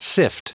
Prononciation du mot sift en anglais (fichier audio)
Prononciation du mot : sift